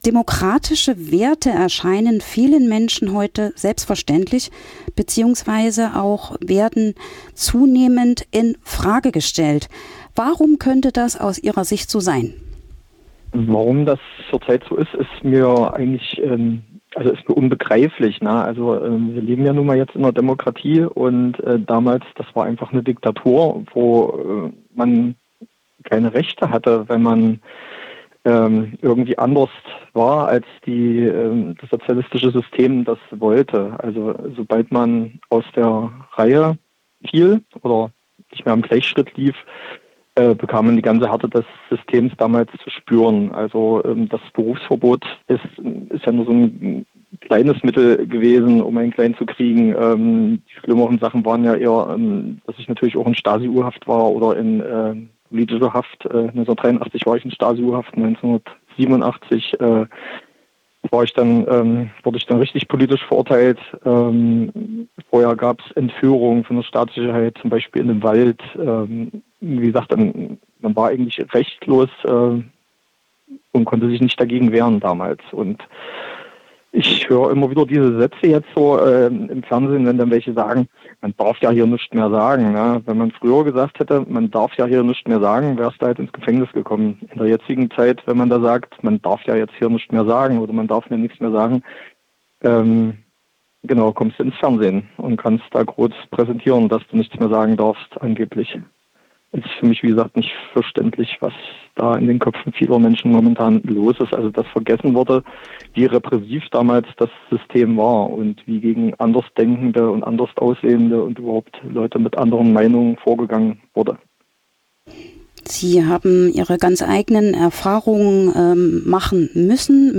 | Interview zur Lesung am 8.